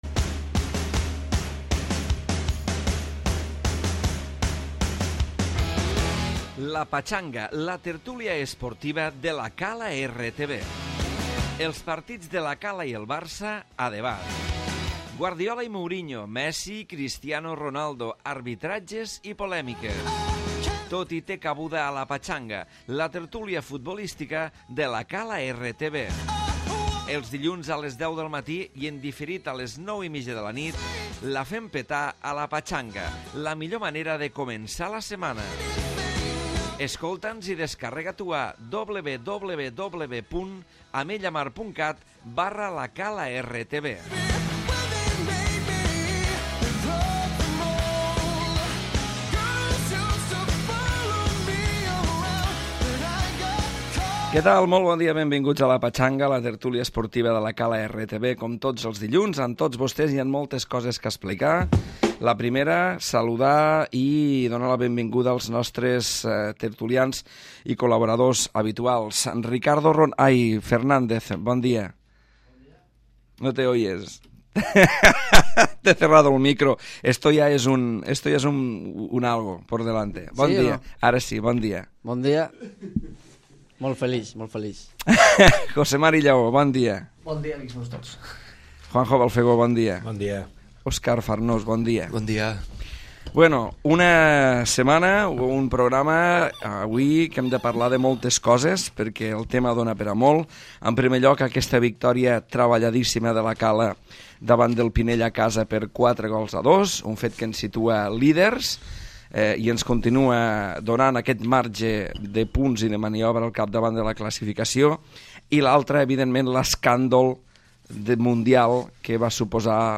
La victòria de La Cala davant del Pinell ha eclipsat avui els partits del barça i Madrid. Demanem disculpes donat que per un problema tècnic no us podem oferir el programa sencer.